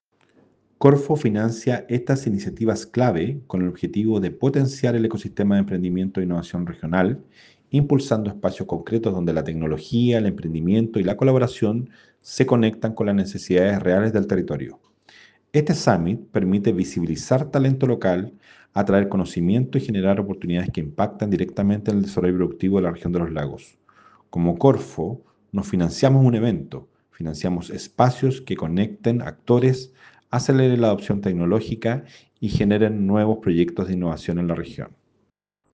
David-Espinoza-director-regional-s-de-Corfo-Los-Lagos.ogg